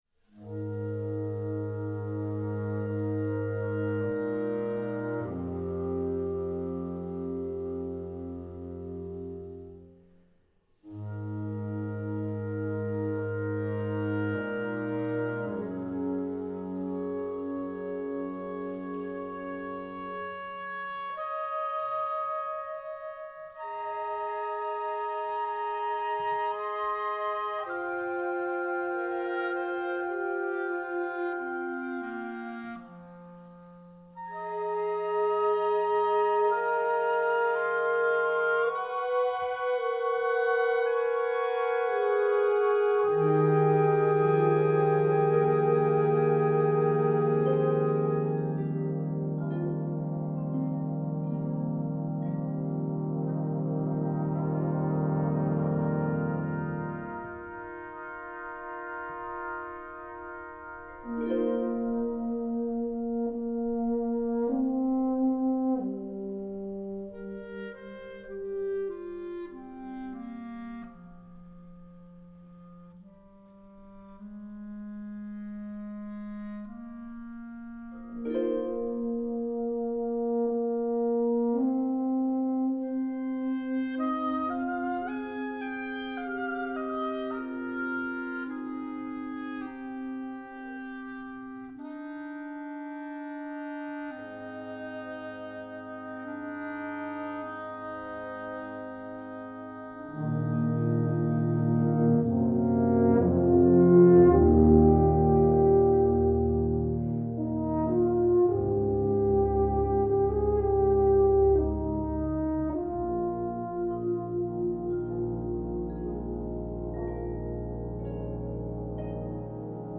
Voicing: Flex Band (Digital Only)